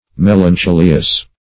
Search Result for " melancholious" : The Collaborative International Dictionary of English v.0.48: Melancholious \Mel`an*cho"li*ous\, a. [Cf. OF. melancholieux.]